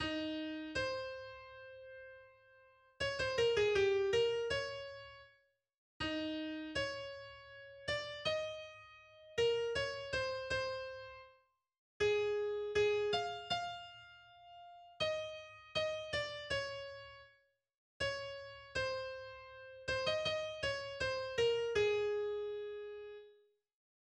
Volkslied